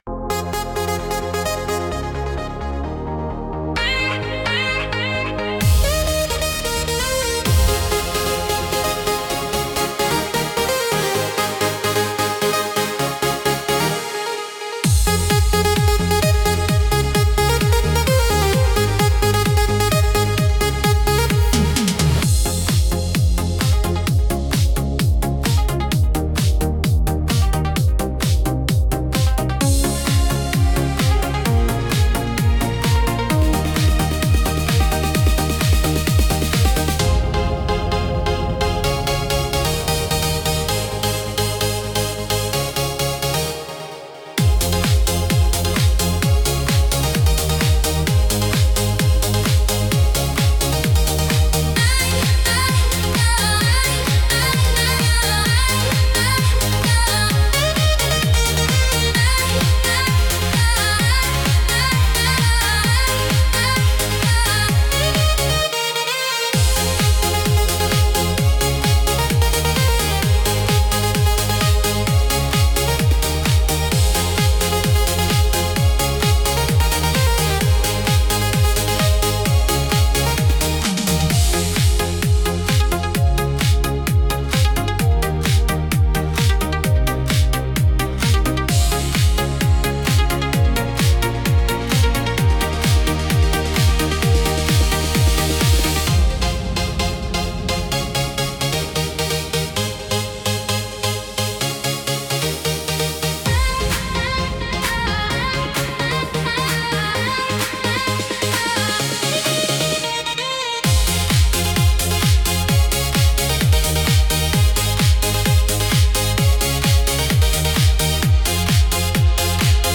Instrumental - Starlight Expressway 2.36